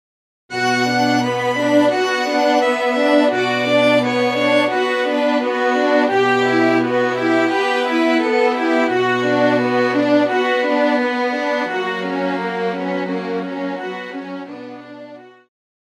Classical
Solo with accompaniment
It is a truly romantic concerto.!